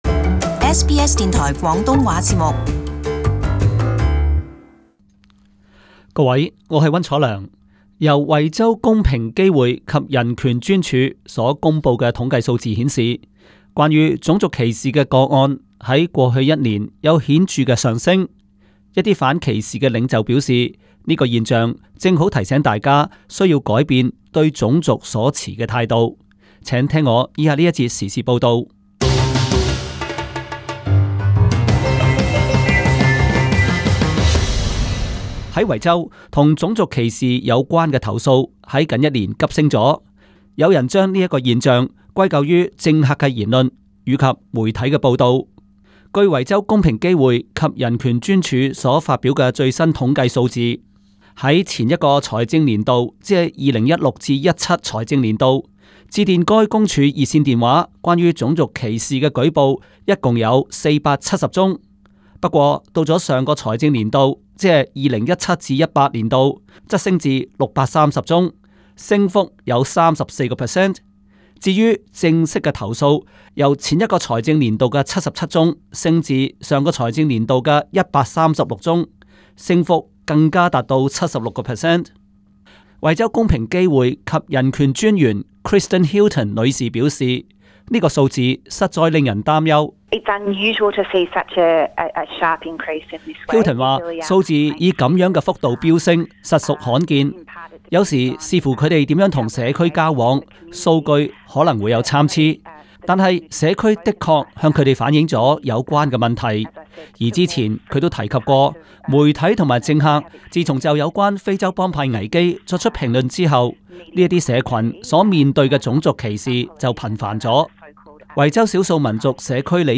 【時事報導】種族歧視個案有急劇上升的趨勢